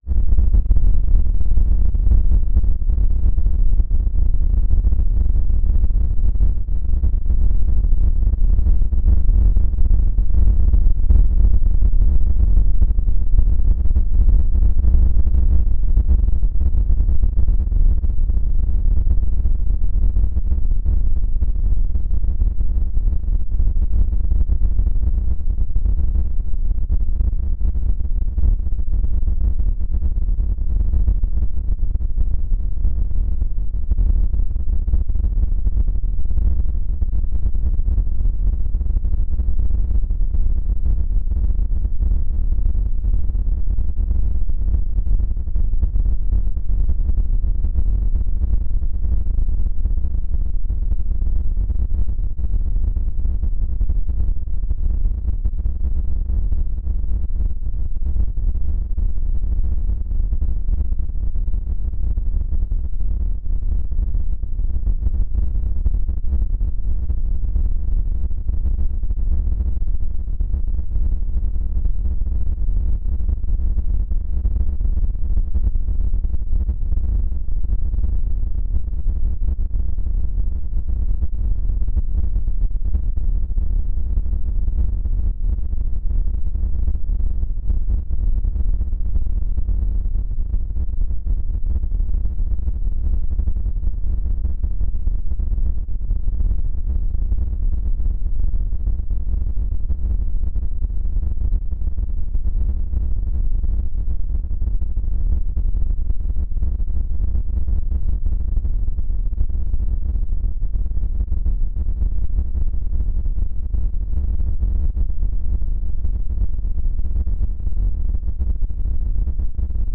electroacoustic music
2-Kanal-Audio